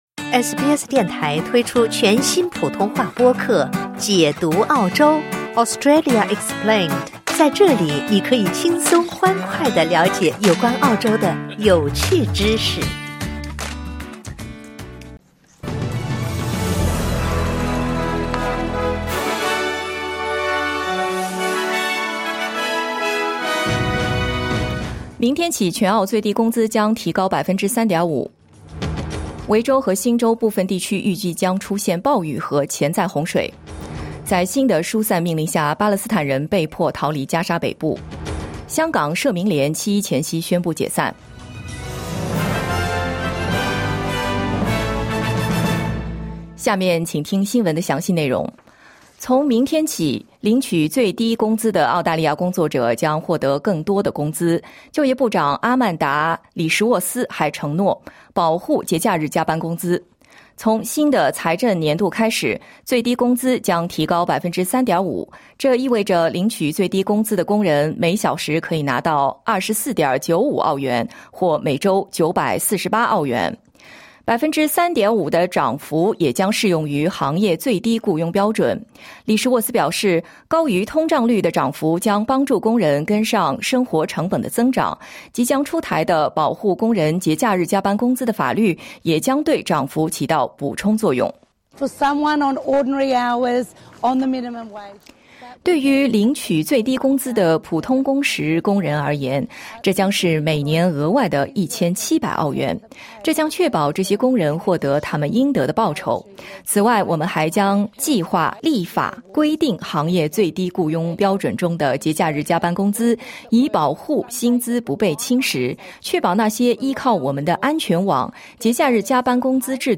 SBS早新闻 (2025年6月30日)